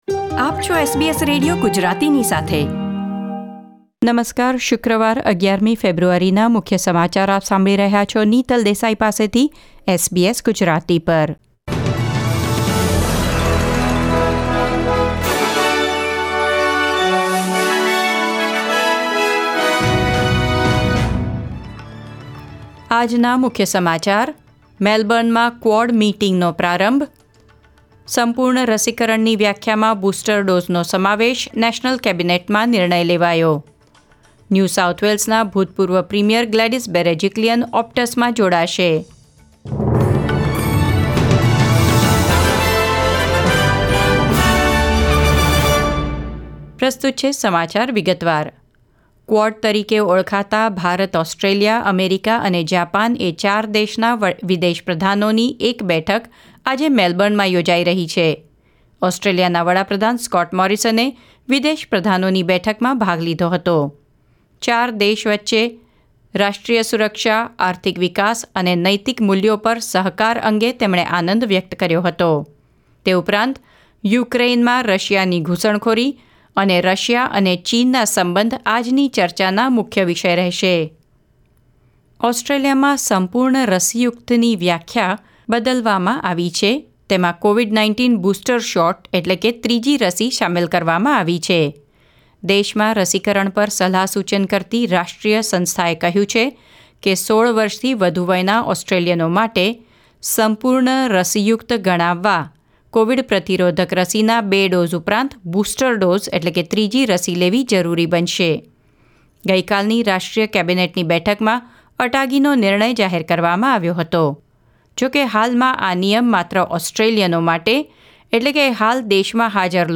SBS Gujarati News Bulletin 11 February 2022